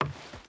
Wood Walk 3.wav